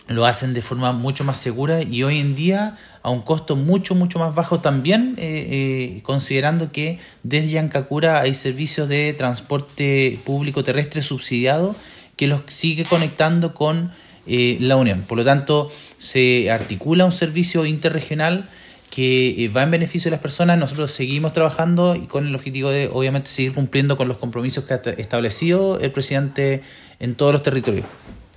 El transporte fluvial en el sector de Llancacura y Currimahuida, se suma a los servicios de transporte subsidiados, con mayores estándares de seguridad como enfatizó el Seremi de Transportes.